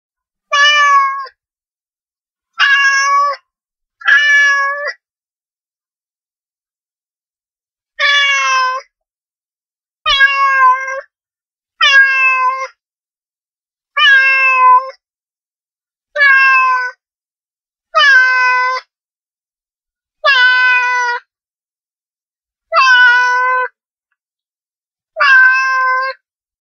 دانلود صدای میو میو گربه 1 از ساعد نیوز با لینک مستقیم و کیفیت بالا
جلوه های صوتی